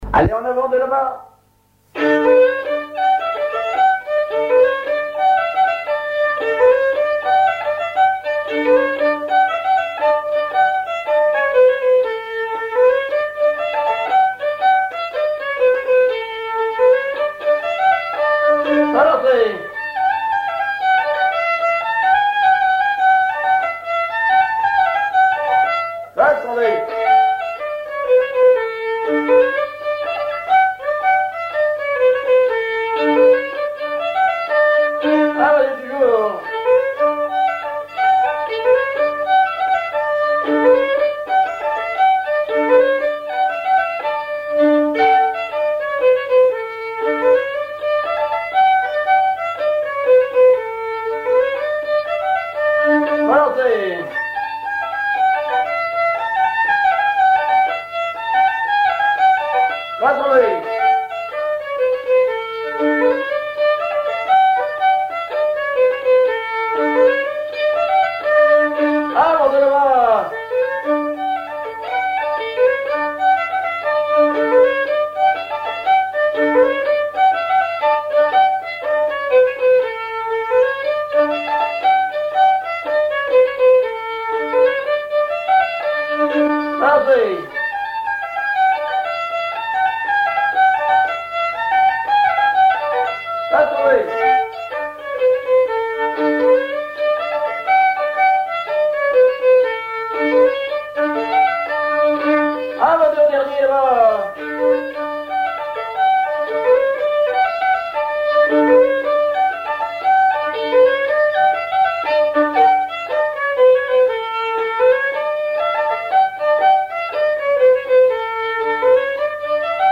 Chants brefs - A danser
danse : branle : avant-deux
Auto-enregistrement
Pièce musicale inédite